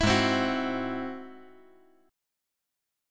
Listen to Ab+7 strummed